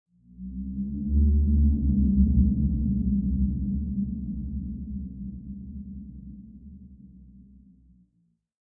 File:Sfx creature glowwhale call 04.ogg - Subnautica Wiki
Sfx_creature_glowwhale_call_04.ogg